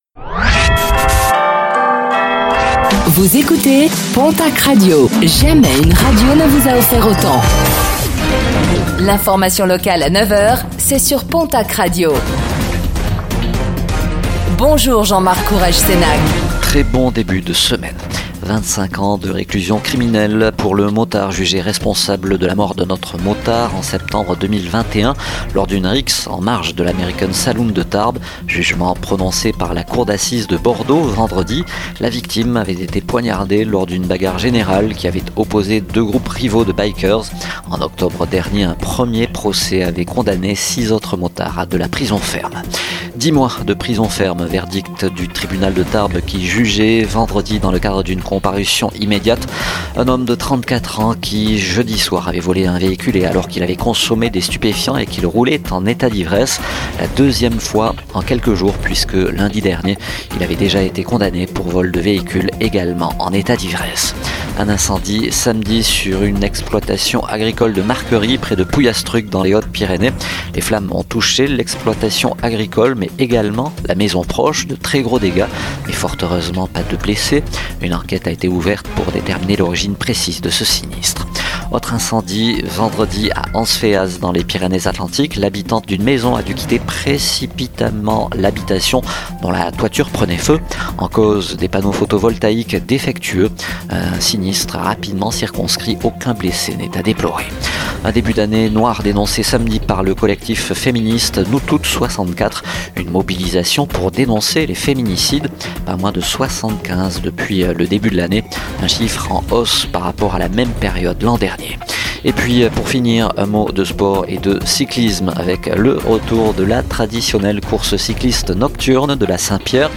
Infos | Lundi 30 juin 2025